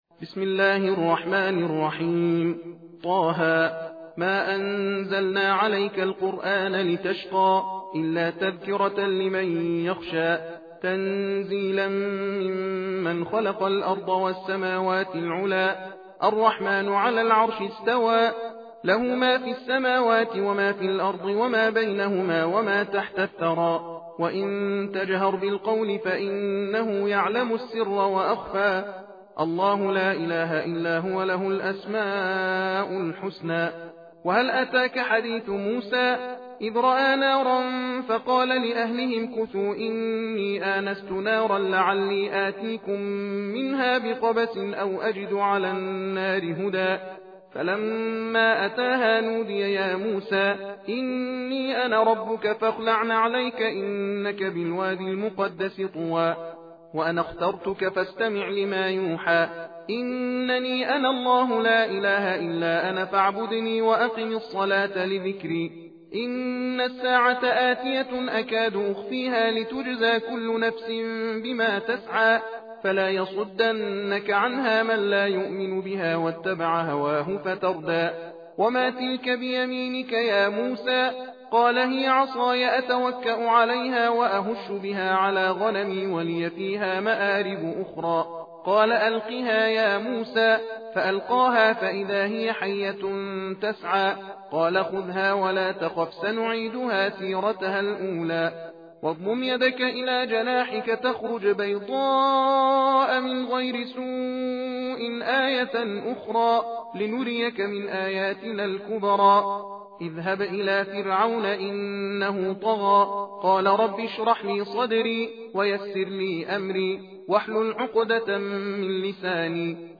تحدیر (تندخوانی) قرآن کریم سوره طه